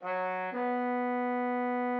The audio file of the first phrase of the aria at the top of this article is incorrect - compare it to the score fragment just below it that correctly shows the first two phrases.
Both the displayed score and the audio are generated from the same LilyPond instructions, so any discrepancies on your end must be caused by inadequate rendering in your browser. Here, "ma-no" is sounded on an eighth F-sharp and a dotted quarter B, just as written.